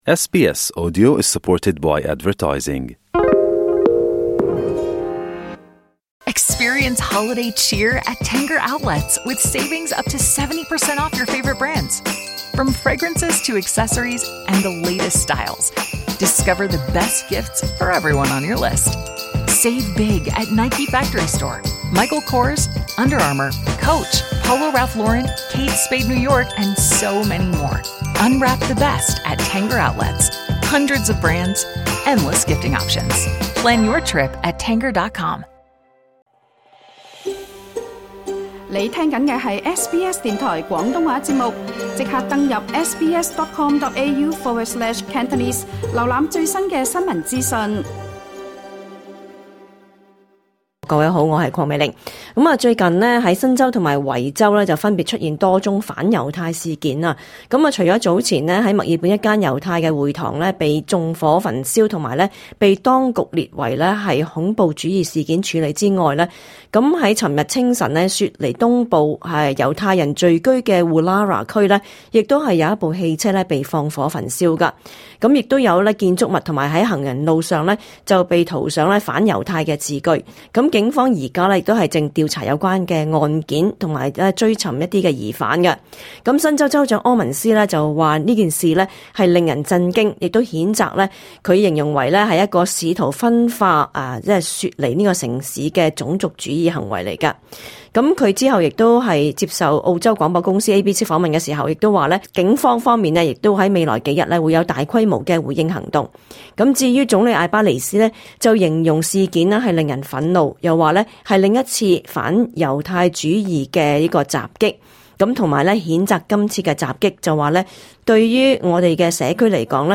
*本節目內嘉賓及聽眾意見並不代表本台立場，而所提供的資訊亦只可以用作參考，個別實際情況需要親自向有關方面查詢為準。